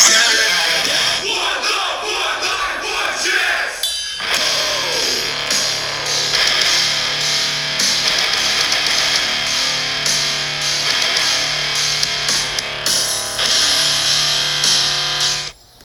Category: Sound FX   Right: Personal
Tags: beat down